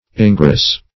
ingross - definition of ingross - synonyms, pronunciation, spelling from Free Dictionary Search Result for " ingross" : The Collaborative International Dictionary of English v.0.48: Ingross \In*gross"\, v. t. See Engross .